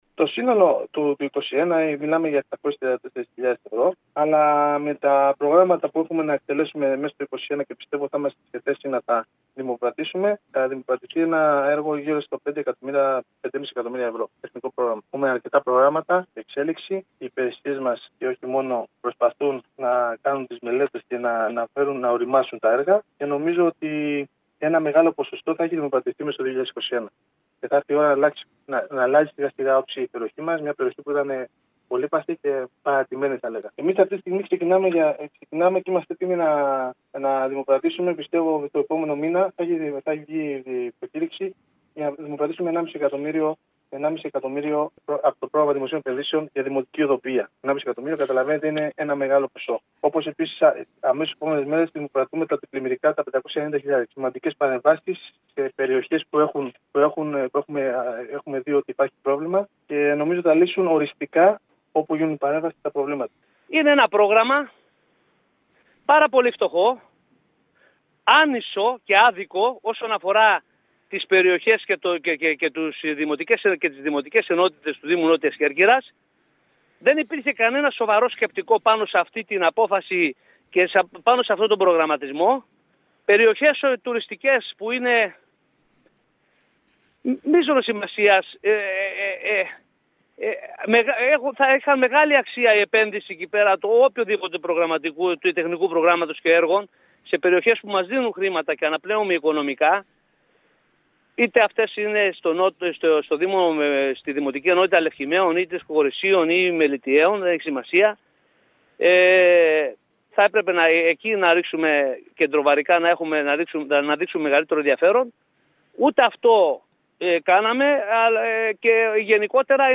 Το Τεχνικό πρόγραμμα ήταν το δεύτερο μεγάλο θέμα που απασχόλησε χθες το Δημοτικό συμβούλιο Νότιας Κέρκυρας. Εγκρίθηκε με τις ψήφους της πλειοψηφίας και τη διαφωνία των παρατάξεων της αντιπολίτευσης με το επιχείρημα ότι είναι μικρό και άνισο ανάμεσα στις διάφορες περιοχές. Ακούμε τον αντιδήμαρχο Τεχνικών υπηρεσιών της Νότιας Κέρκυρας, Νίκο Κουρτέση, και τους επικεφαλής παρατάξεων Γιάννη Πανδή και Γιώργο Κουλούρη.